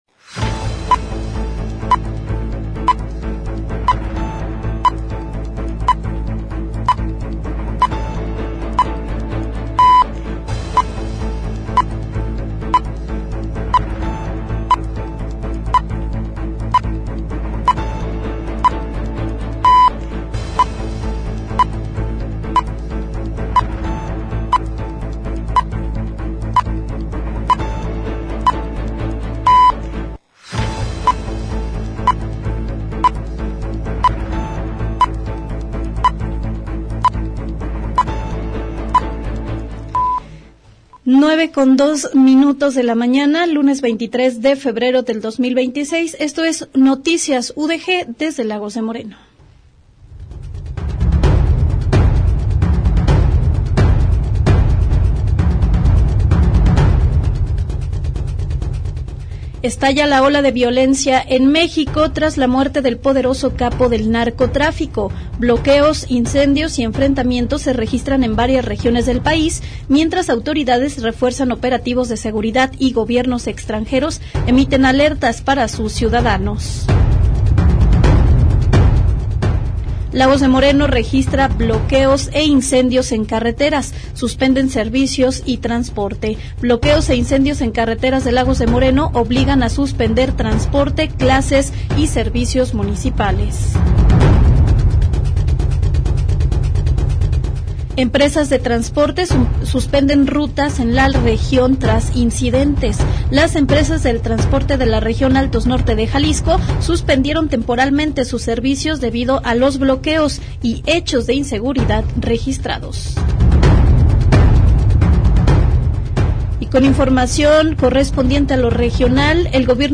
Espacio periodístico dedicado a recopilar, analizar y difundir los acontecimientos más relevantes de una comunidad específica. Ofrece cobertura puntual de los hechos más importantes a nivel local y regional.
A través de reportajes, entrevistas, enlaces en vivo y análisis, acerca la información a la audiencia de manera clara manteniendo a la población informada sobre lo que sucede en su entorno inmediato: temas sociales, políticos, económicos, culturales, educativos y de seguridad que impactan directamente en la vida diaria de los ciudadanos. · GÉNERO: Informativo